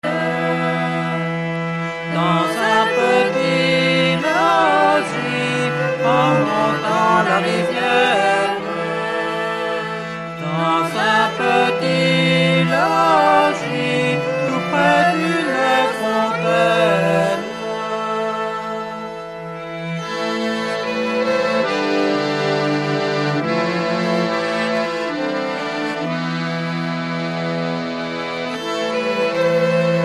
Musique : Traditionnel
Interprètes : Kouerien Sant-Yann, Les Cailloux
Origine : Québec, Bretagne
Danse : An dro, Rond de Sautron
kouerien - folk breton - 09-an dro en montant la riviere.mp3